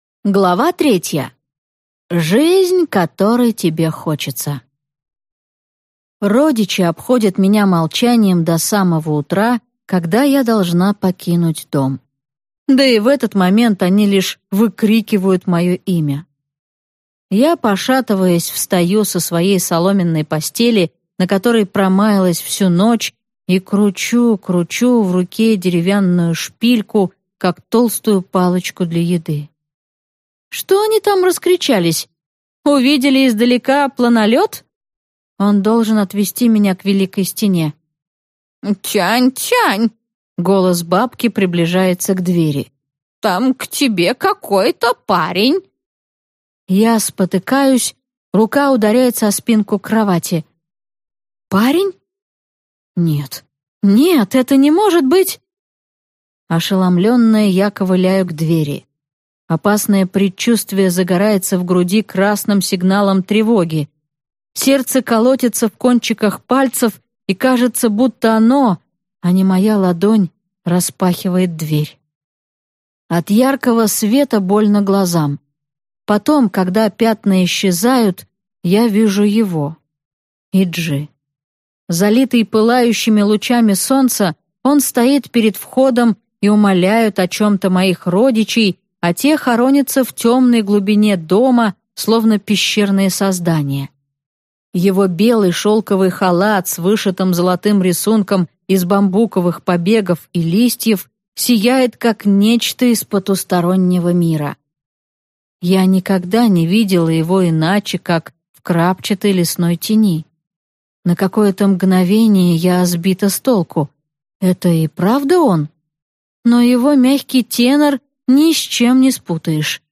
Аудиокнига Железная вдова | Библиотека аудиокниг